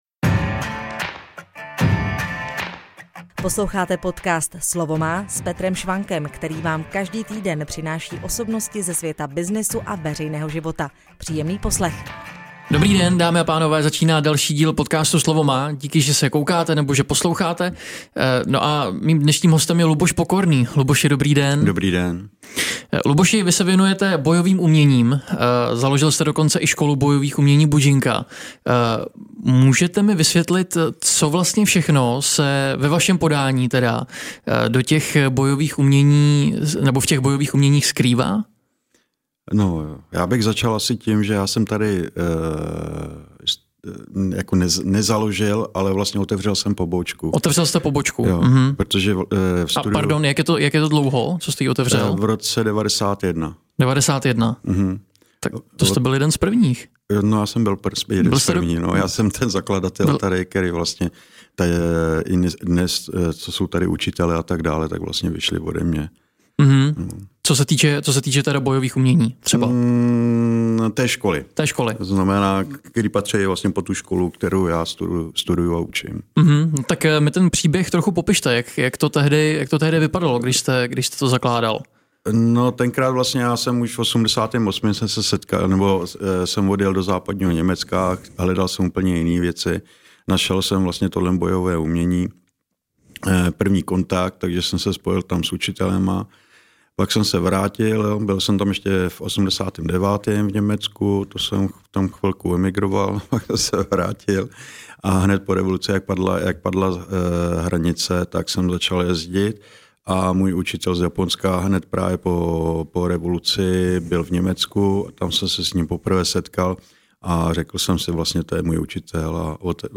Proč je podle něj dobré znát bojové umění? A v čem vlastně tkví síla tohoto sportu? Dozvíte se v aktuálním rozhovoru.